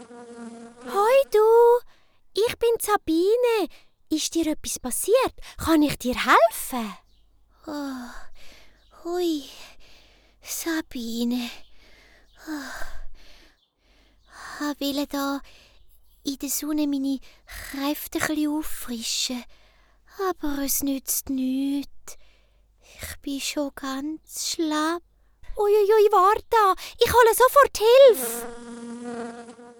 ★ Abentüür uf de Wiese Folge 1 ★ Dialekt Hörspiel ★ Löffelspitzer